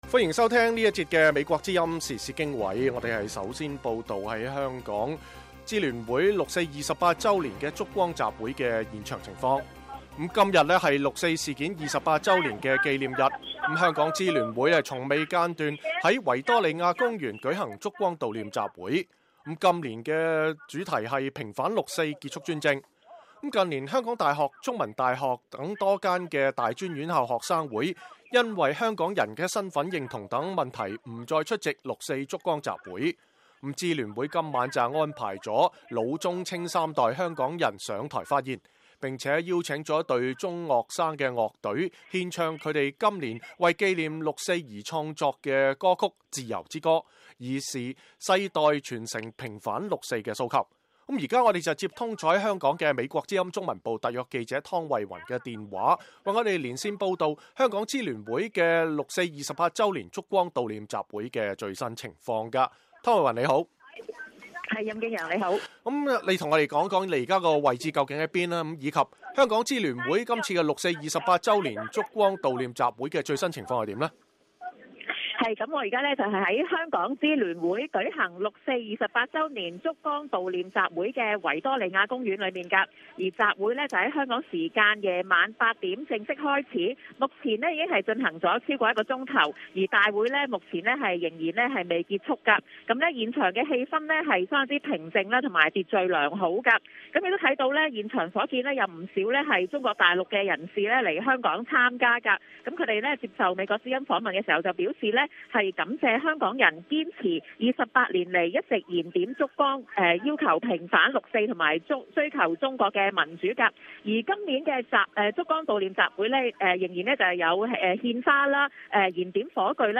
香港支聯會六四28周年燭光集會現場報道